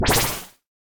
whoosh.ogg